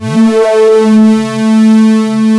OSCAR 10 A3.wav